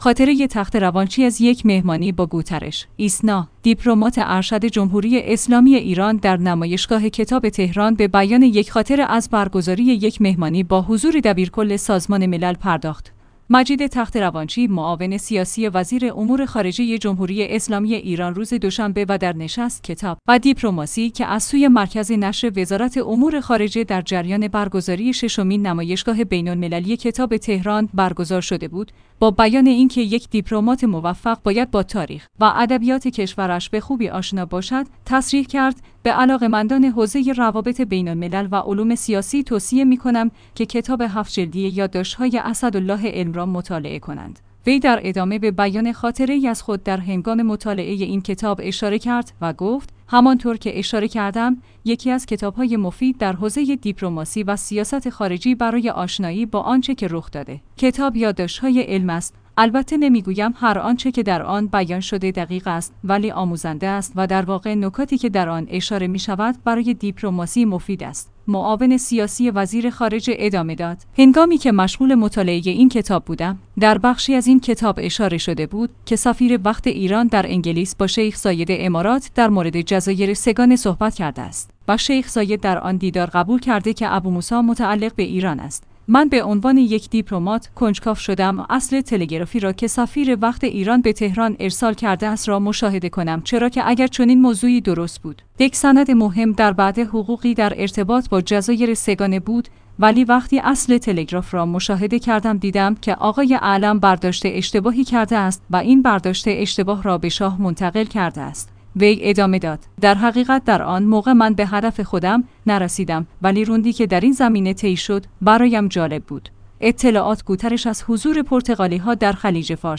ایسنا/ دیپلمات ارشد جمهوری اسلامی ایران در نمایشگاه کتاب تهران به بیان یک خاطره از برگزاری یک مهمانی با حضور دبیرکل سازمان ملل پرداخت.